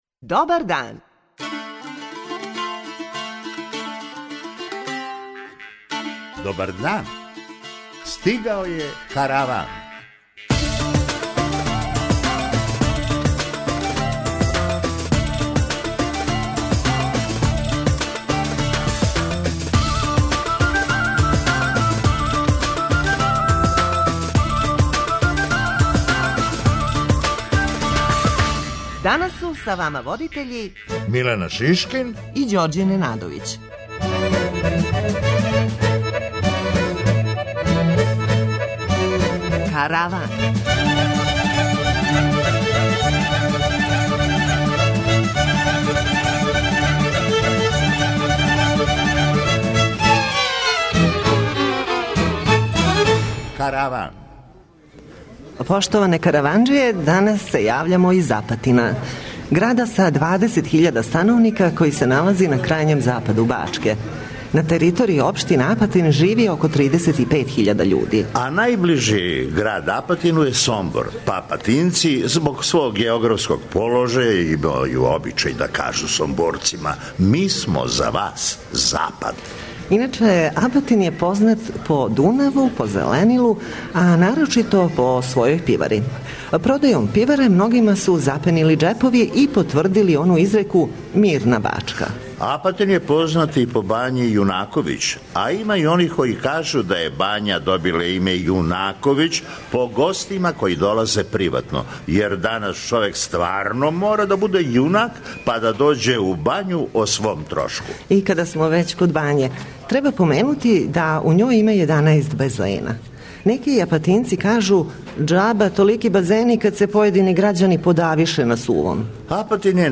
А ми данас у Апатину, уживо!